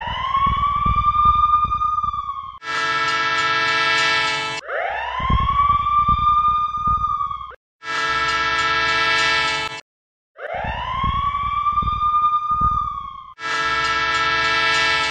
Klingelton Alarm Cell Phone
Kategorien SMS Töne